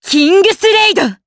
Xerah-Vox_Kingsraid_Madness_jp.wav